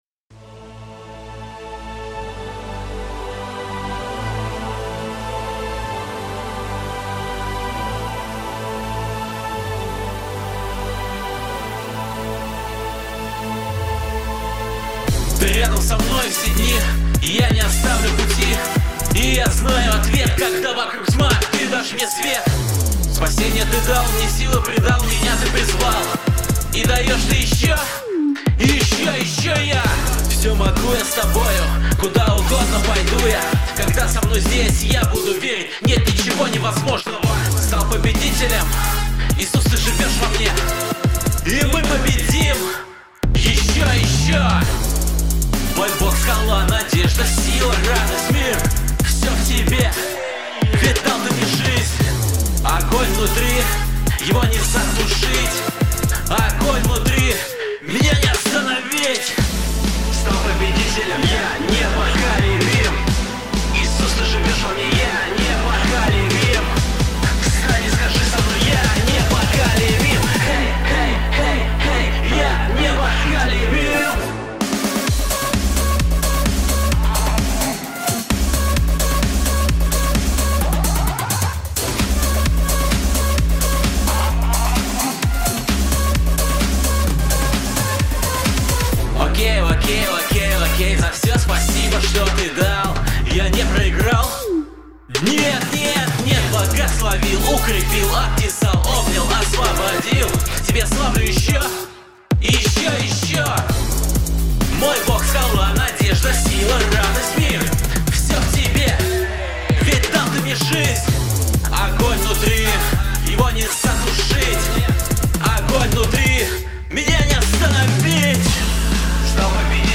574 просмотра 757 прослушиваний 41 скачиваний BPM: 130